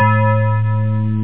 home *** CD-ROM | disk | FTP | other *** search / AMOS PD CD / amospdcd.iso / samples / instruments / bell1 ( .mp3 ) < prev next > Amiga 8-bit Sampled Voice | 1990-10-26 | 10KB | 1 channel | 8,363 sample rate | 1 second
bell1.mp3